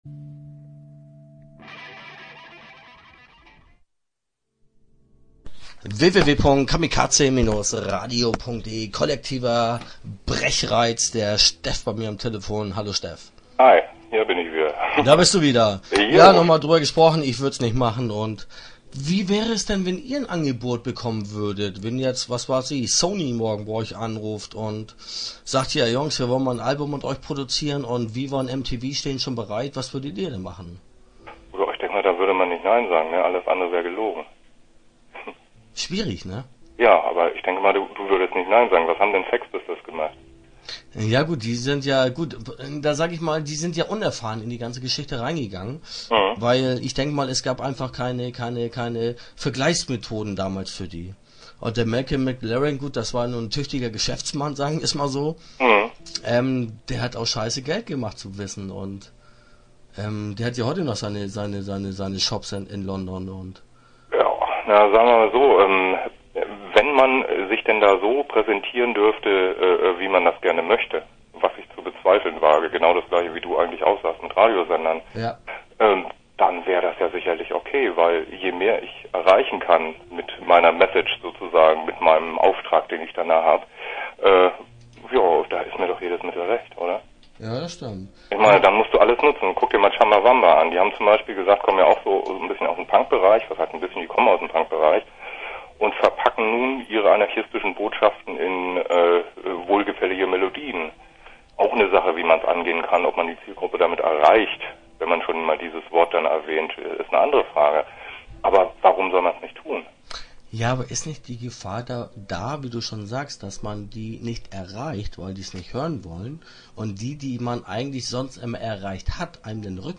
Interview Teil 1 (6:54)